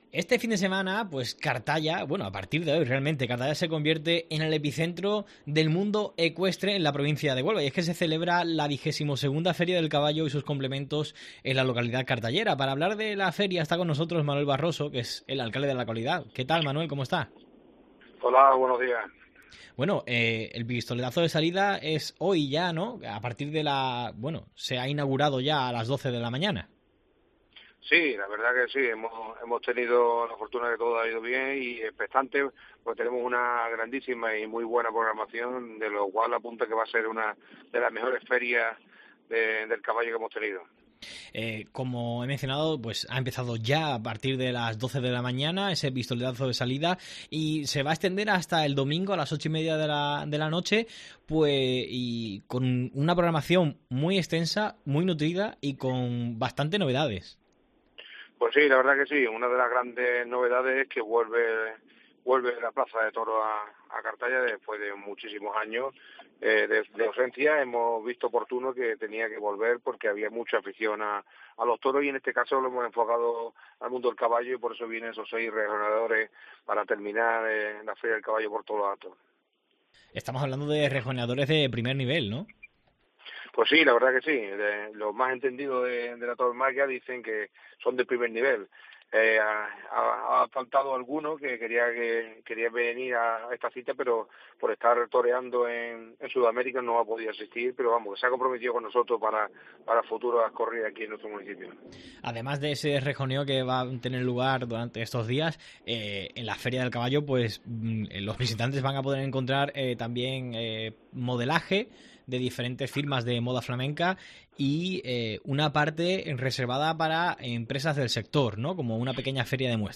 Este viernes arranca la XXII Feria del Caballo y sus complementos en Cartaya. Hablamos con Manuel Barroso, alcalde de la localidad, para conocer las novedades de este año.